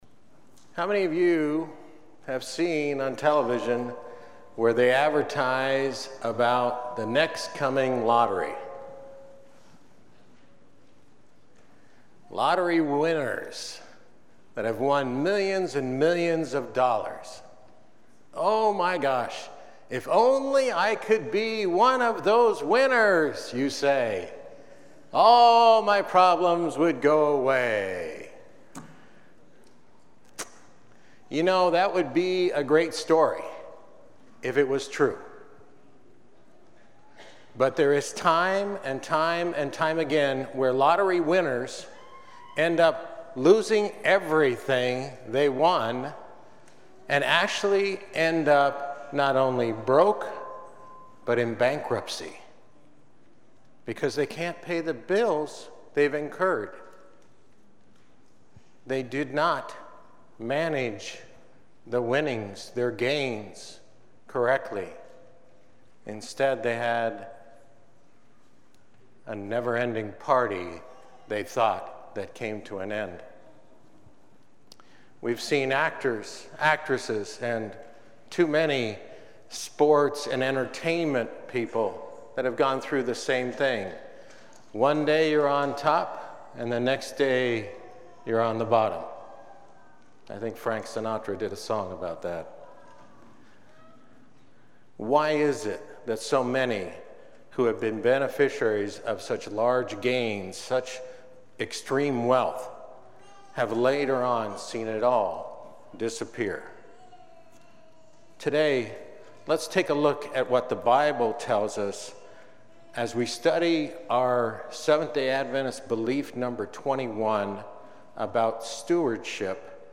Sabbath Sermons